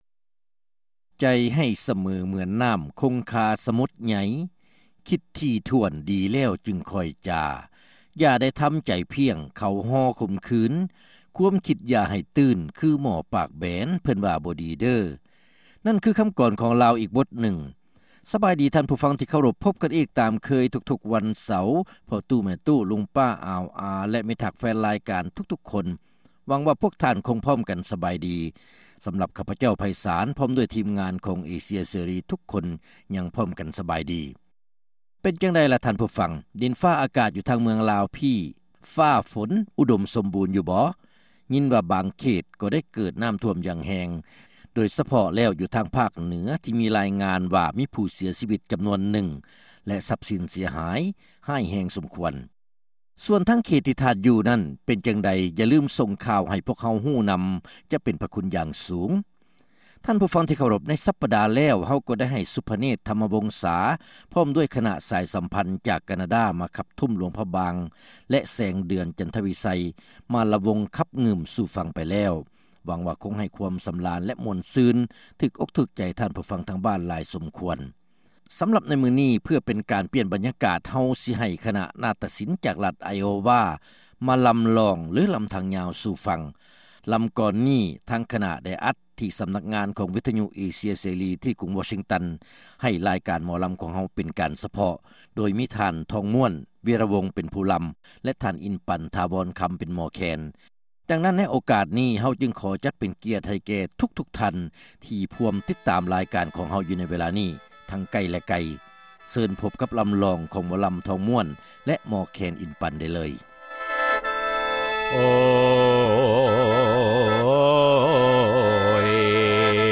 ຣາຍການໜໍລຳ ປະຈຳສັປະດາ ວັນທີ 22 ເດືອນ ກັນຍາ ປີ 2006